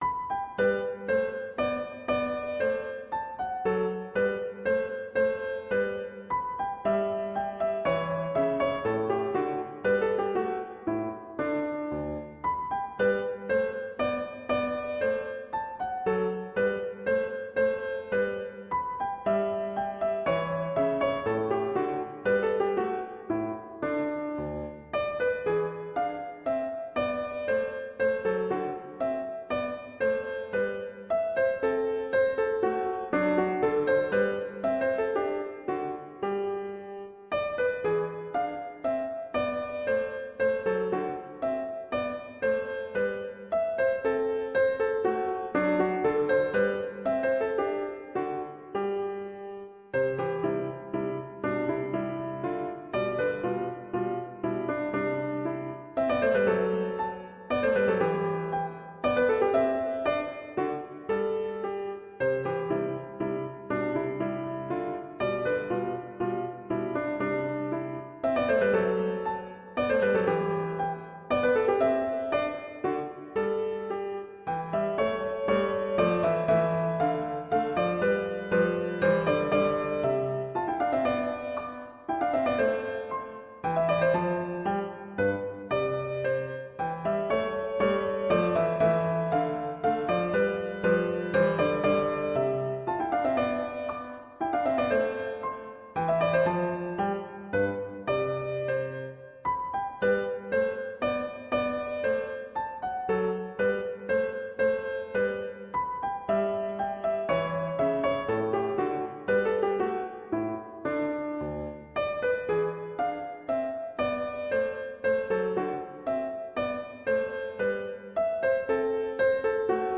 × キルンベルガー第1と、全く合わないということだけは確か。